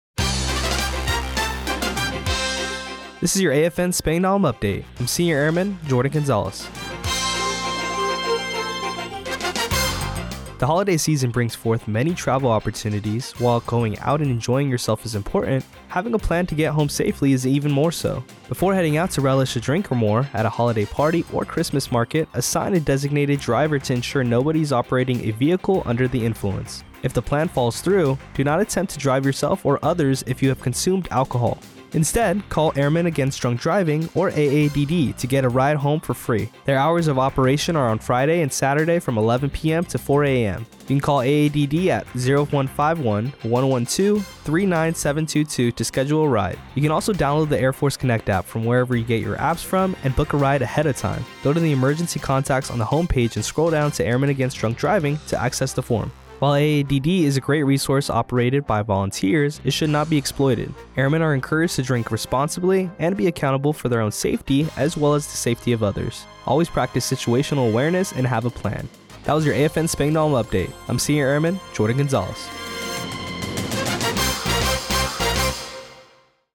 The following was the radio news report for AFN Spangdahlem for Dec. 20, 2024.